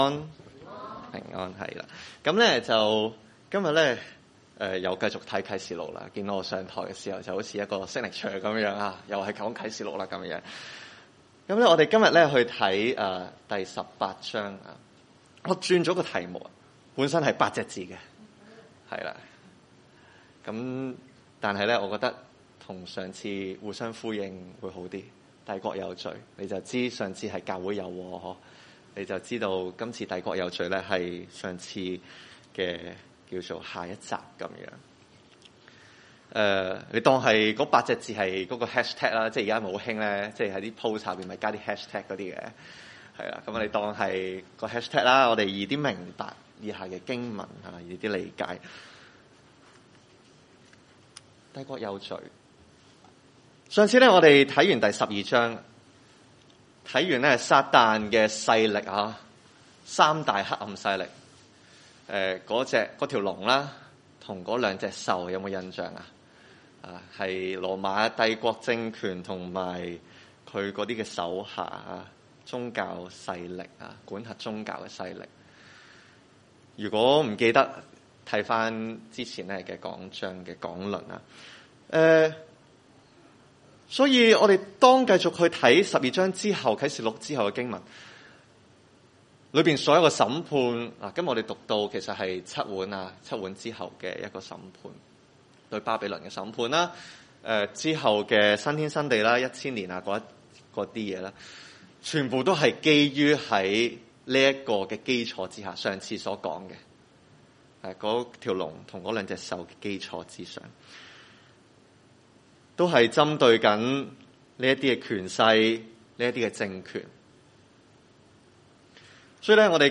啟 十八 21-24 崇拜類別: 主日午堂崇拜 18:21 有 一 位 大 力 的 天 使 舉 起 一 塊 石 頭 、 好 像 大 磨 石 、 扔 在 海 裡 、 說 、 巴 比 倫 大 城 、 也 必 這 樣 猛 力 的 被 扔 下 去 、 決 不 能 再 見 了 。